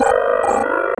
Highengine.wav